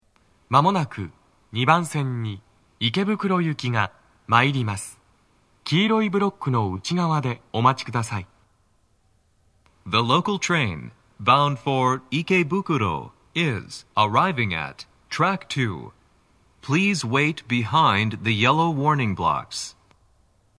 スピーカー種類 TOA天井型
鳴動は、やや遅めです。
2番線 池袋方面 接近放送 【男声